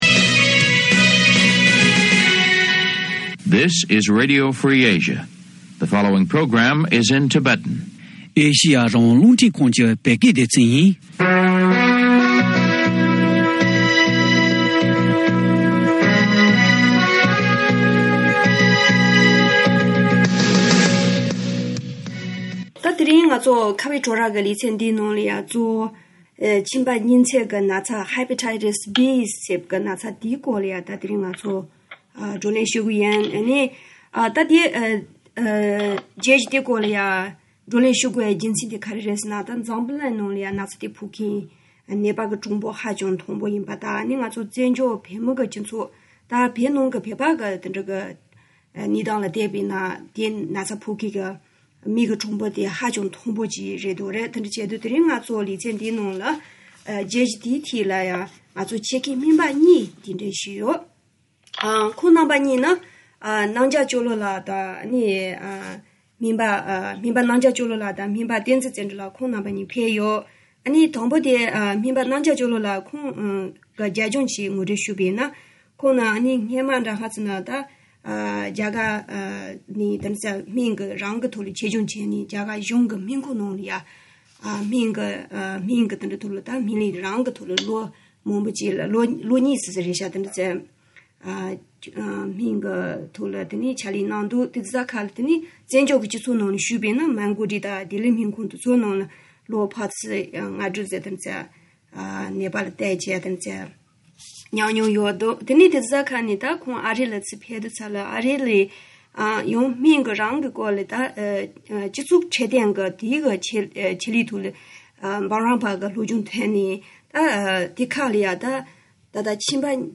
ཆེད་མཁས་སྨན་པ་གཉིས་